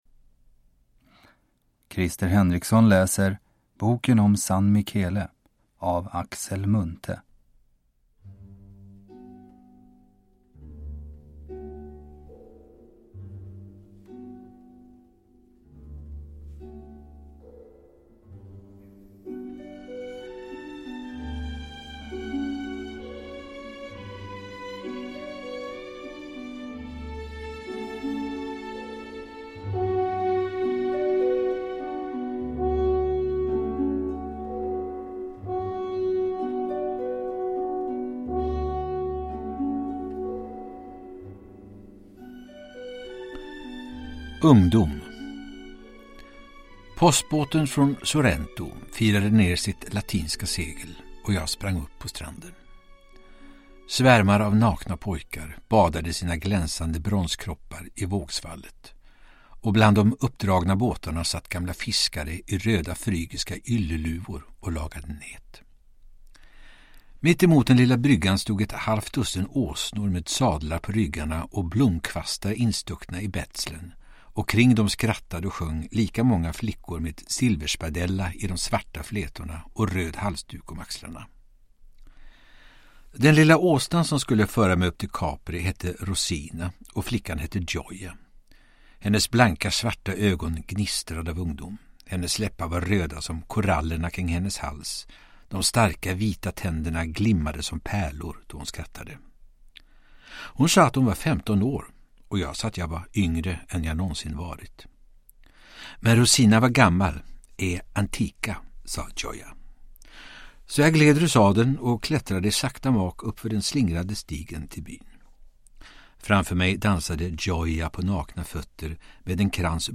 Uppläsare: Krister Henriksson
Ljudbok